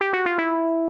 negative_beeps.ogg